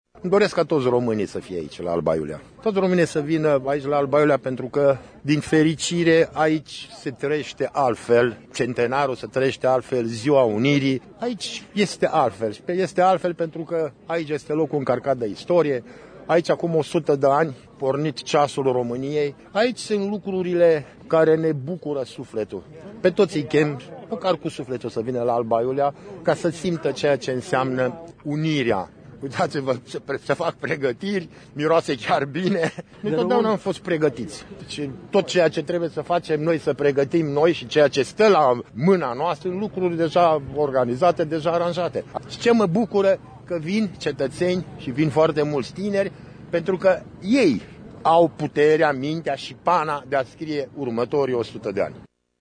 Primarul, Mircea Hava, îi învită pe toți românii să vină la Alba Iulia: